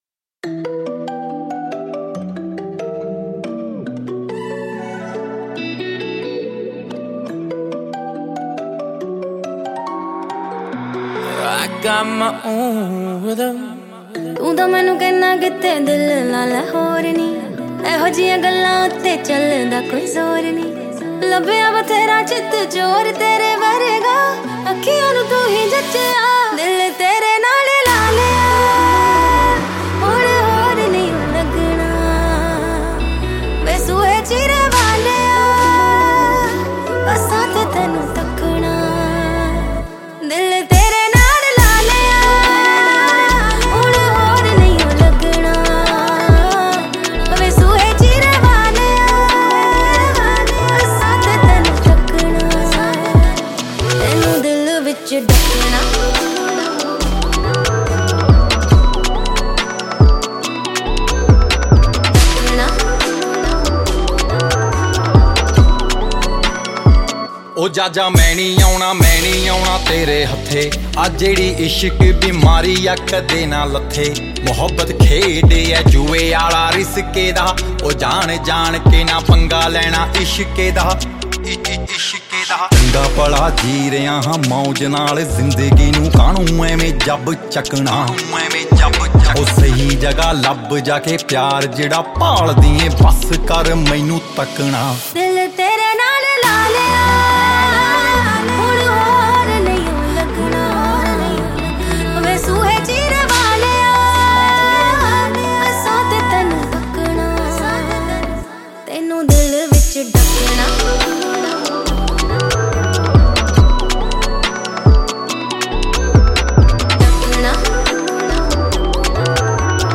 Punjabi